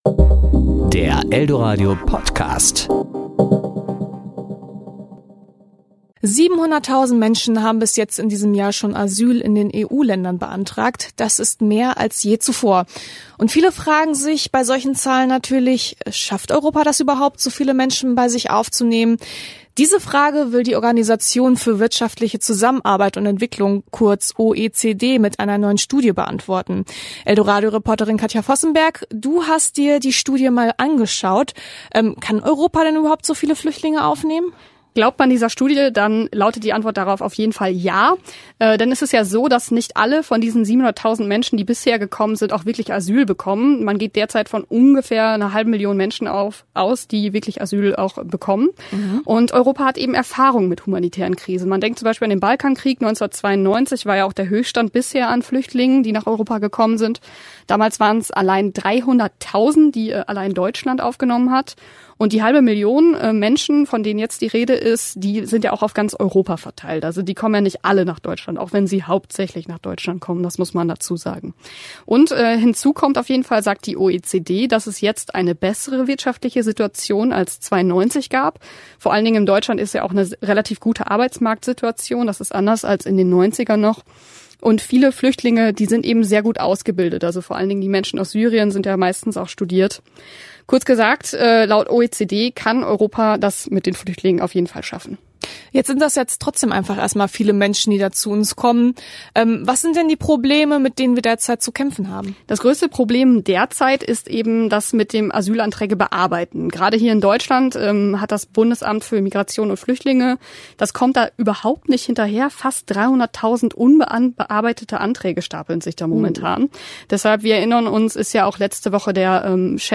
Kollegengespräch  Ressort